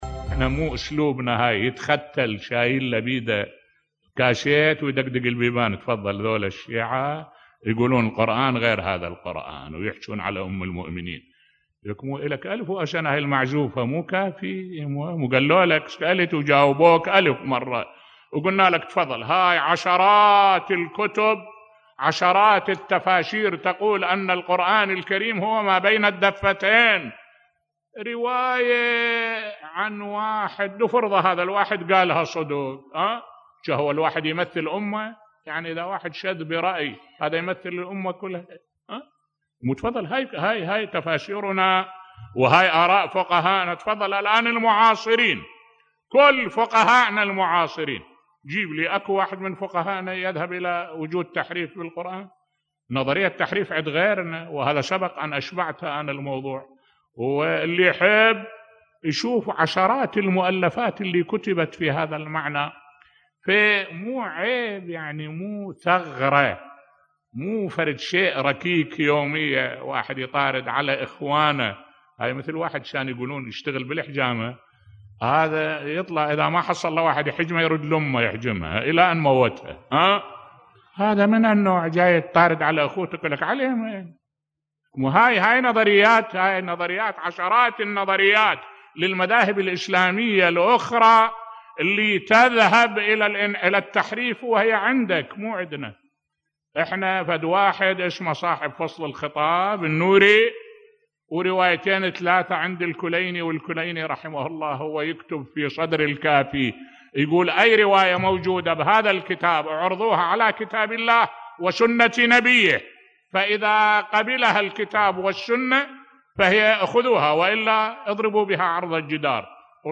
ملف صوتی لماذا الإصرار على اتهام الشيعة بالقول بتحريف القرآن بصوت الشيخ الدكتور أحمد الوائلي